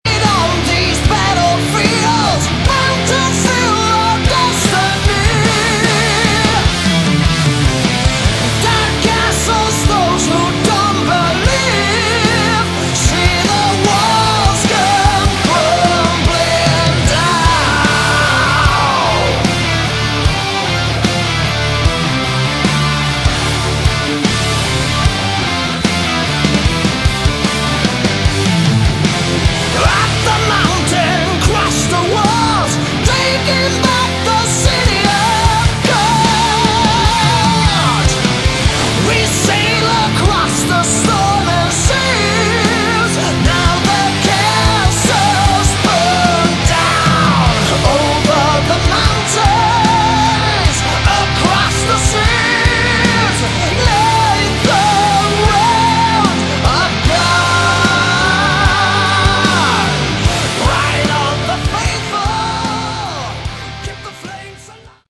Category: Melodic Metal / Prog / Power Metal
guitars
keyboards
drums
bass
vocals
violin